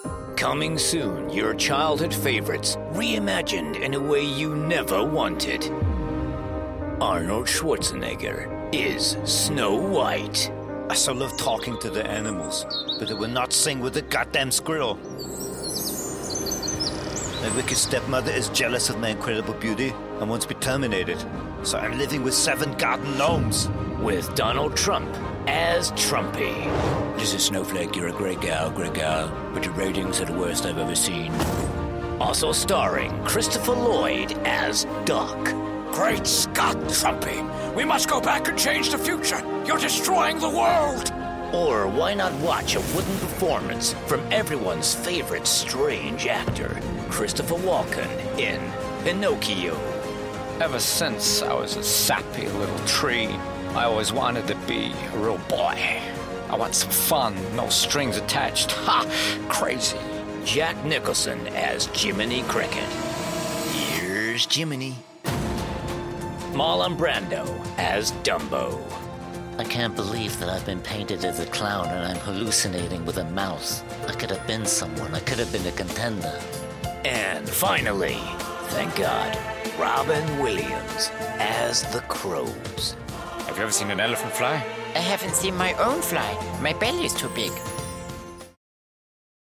Animation
My genuine, authoritative and trustworthy tone has brought brand credibility to many high profile clients and earned me three award nominations.
Rode NT1-a Condenser Mic
Middle-Aged
BaritoneBassDeep